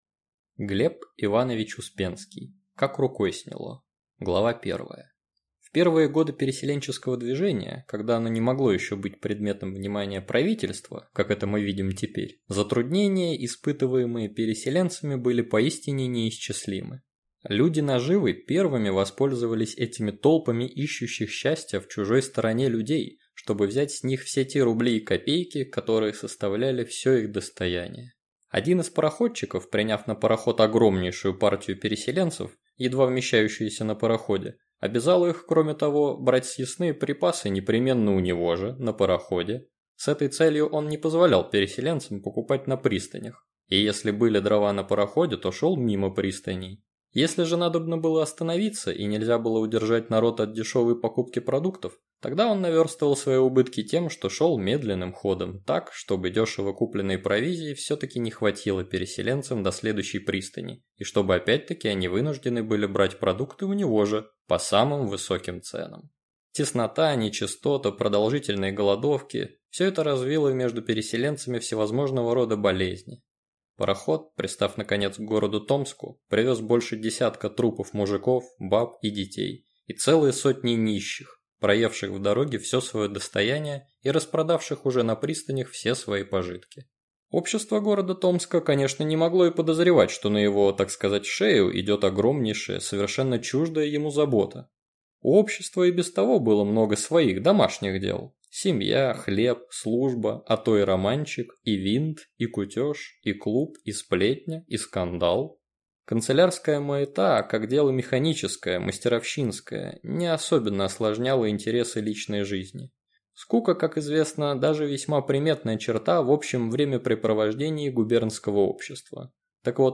Аудиокнига Как рукой сняло!